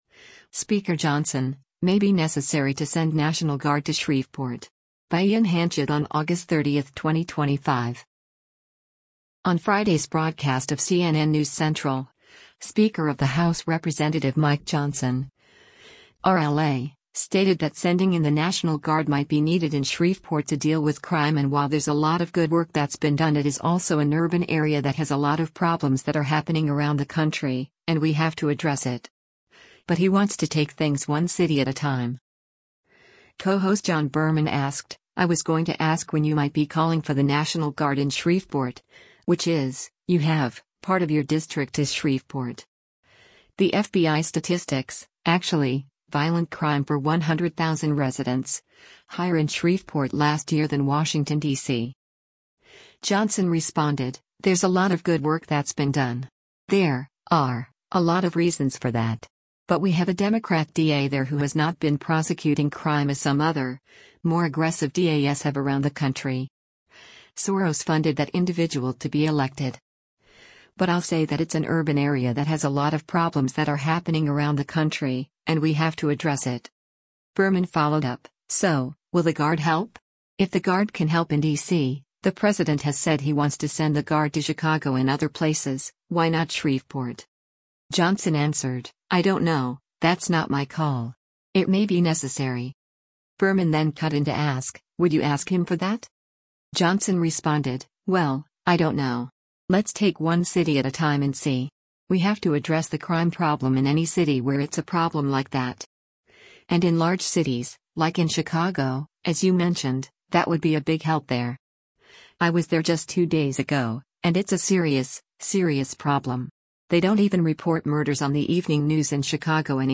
Co-host John Berman asked, “I was going to ask when you might be calling for the National Guard in Shreveport, which is — you have —  part of your district is Shreveport. The FBI statistics, actually, violent crime per 100,000 residents, higher in Shreveport last year than Washington, D.C.”